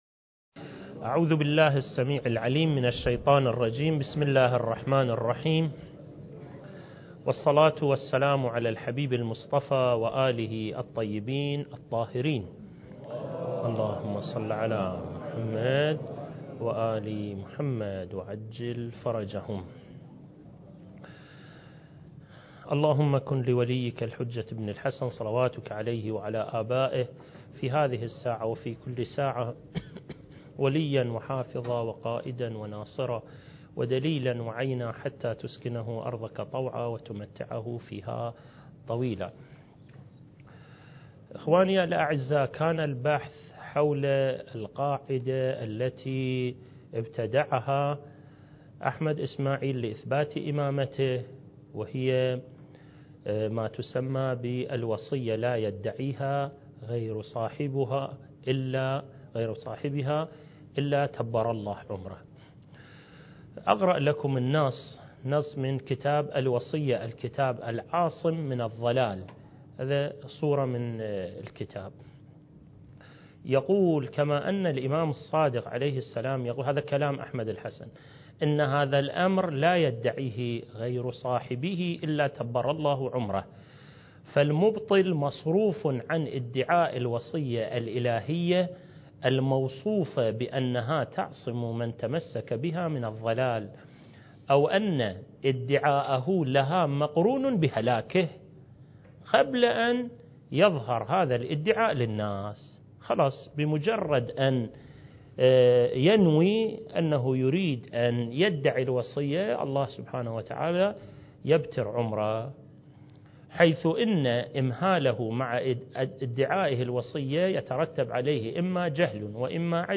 المكان: مؤسسة الإمام الحسن المجتبى (عليه السلام) - النجف الأشرف دورة منهجية في القضايا المهدوية (رد على أدعياء المهدوية) (6) التاريخ: 1443 للهجرة